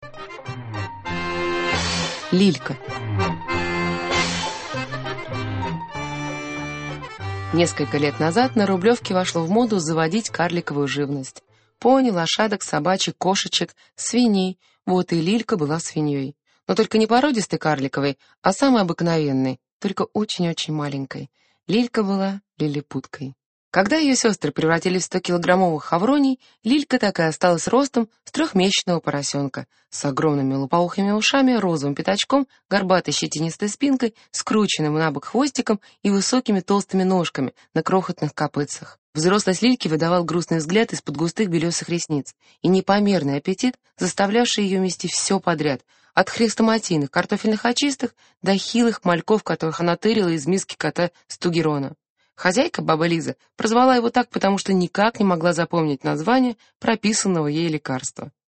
Аудиокнига Заморочки по-рублевски | Библиотека аудиокниг